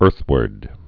(ûrthwərd)